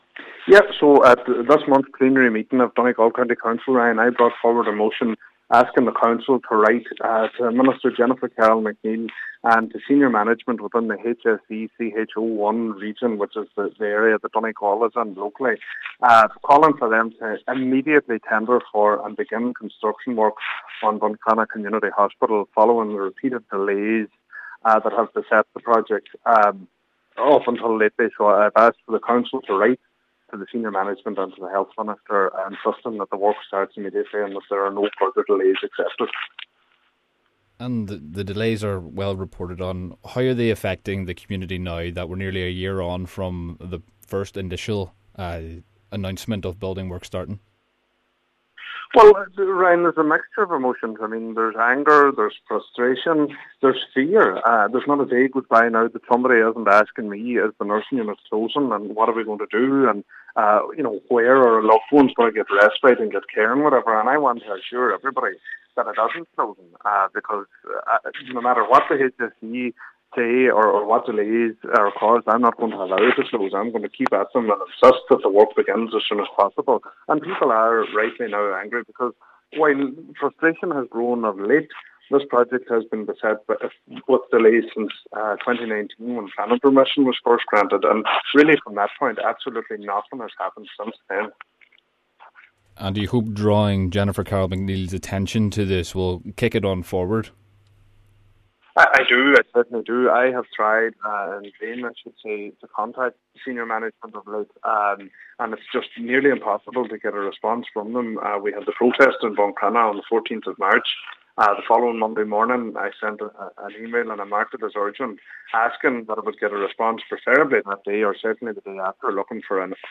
Cllr Bradley adds that the lack of engagement from the HSE with local representatives is causing concern within the community: